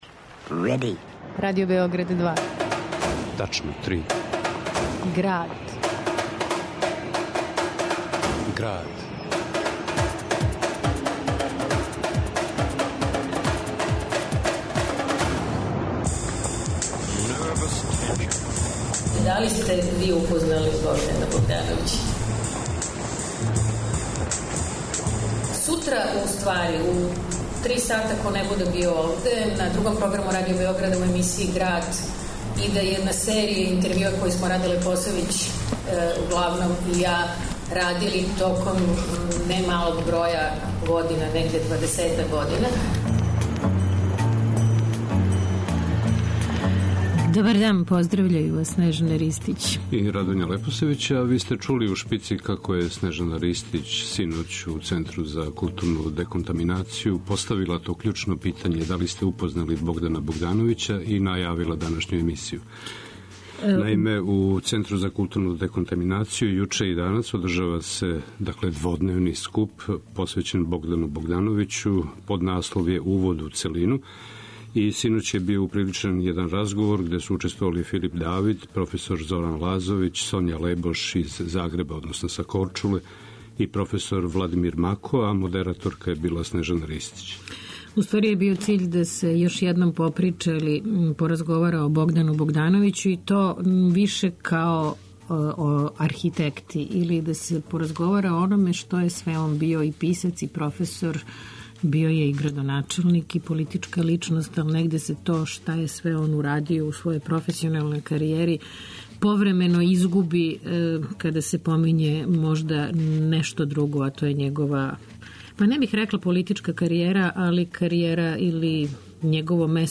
У Граду , на документарним снимцима чућемо како је о себи, архитектури и свету говорио Богдан Богдановић .